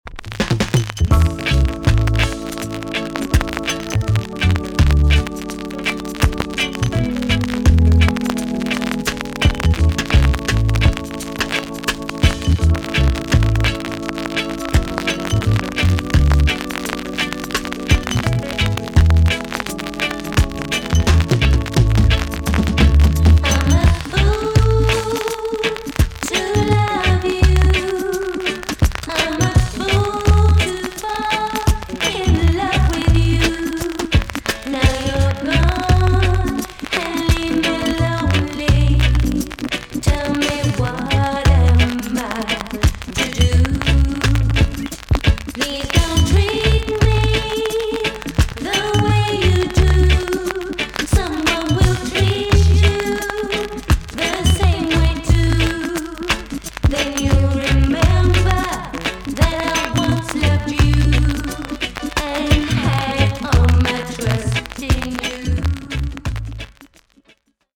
TOP >REGGAE & ROOTS
VG ok 全体的に軽いチリノイズが入ります。
UK , NICE LOVERS ROCK TUNE!!